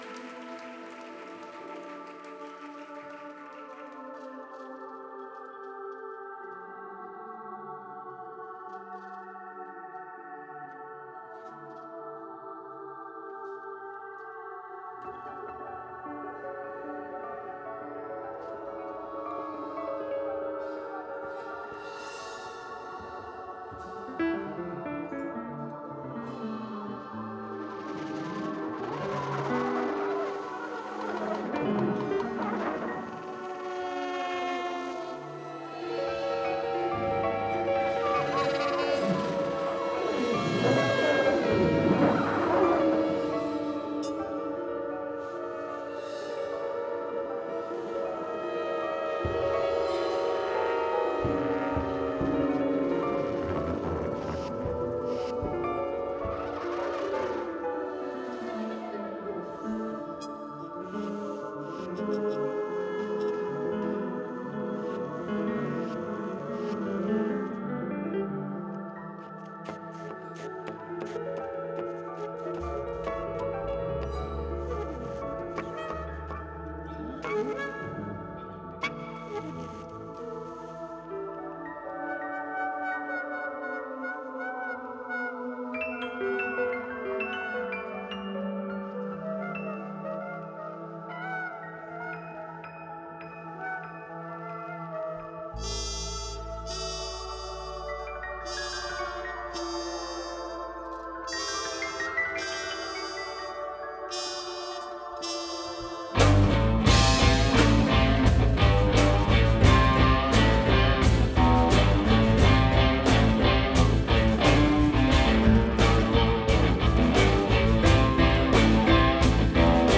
Тип альбома: Студийный
Жанр: Post-Bop
trumpet